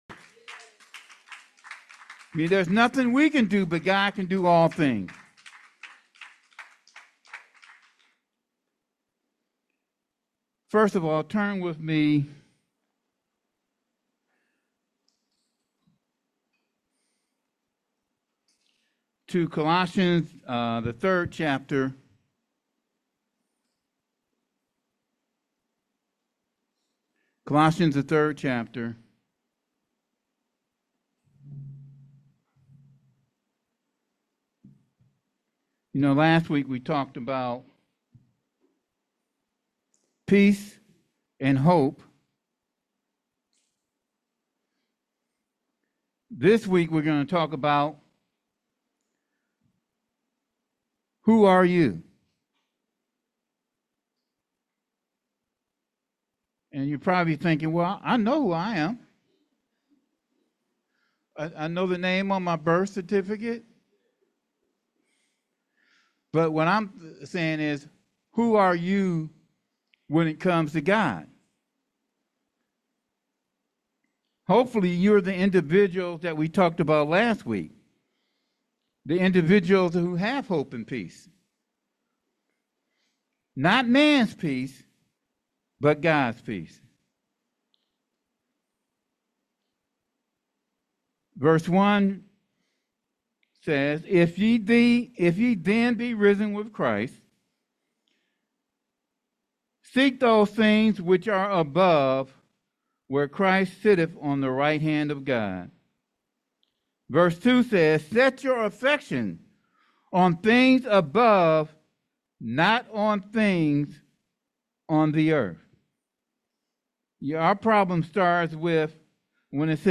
The sermon is primarily an exposition of a single chapter, with other verses used for support or further reading.